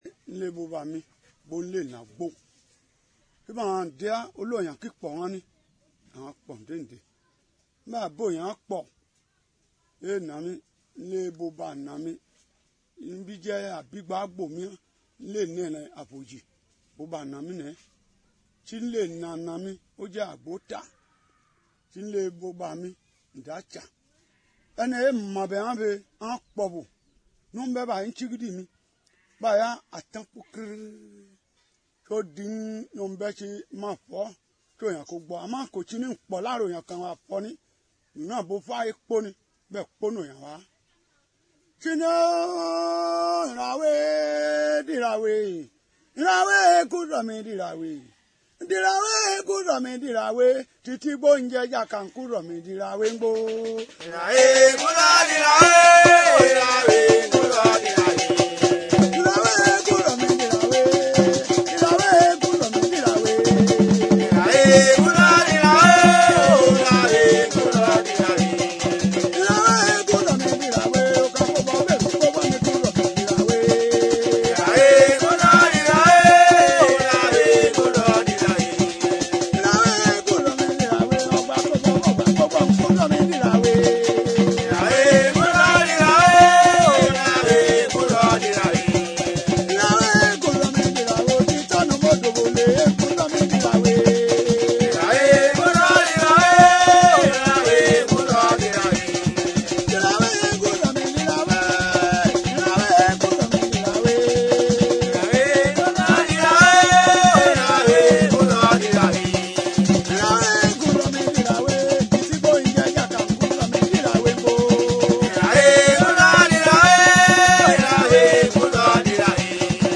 Chants de deuil Akpokpo